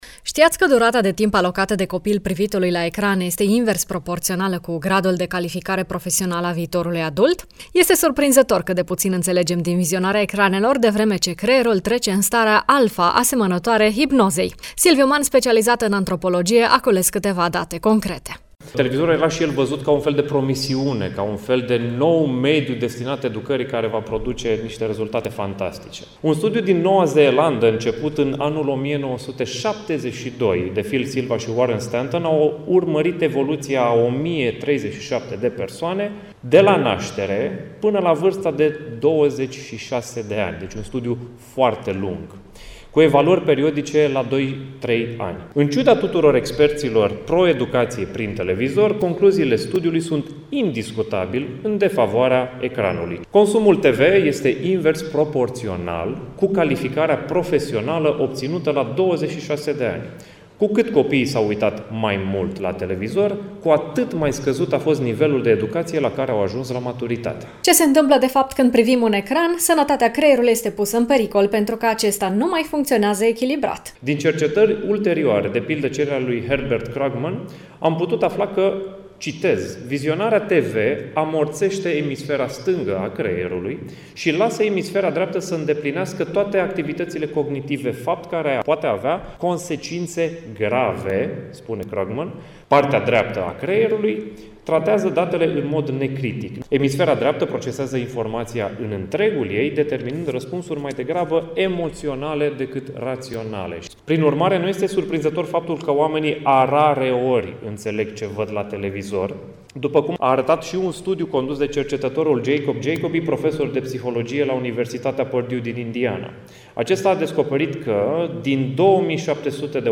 antropolog